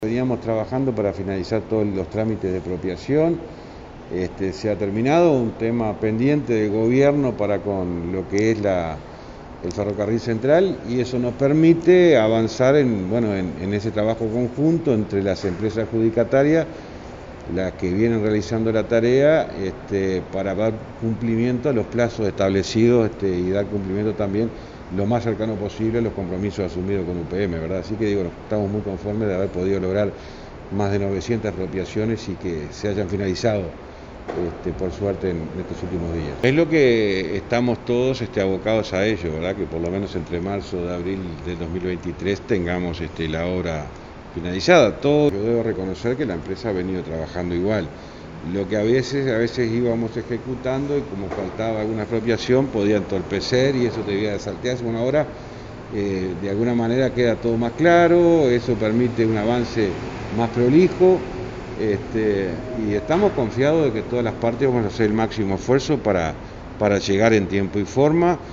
Escuche a José Luis Falero aquí